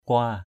/kʊa:/ (đg.) chải, cào = peigner, racler. kua akaok k&% a_k<K chải đầu = se peigner. kua padai k&% p=d cào lúa = racler le riz.
kua.mp3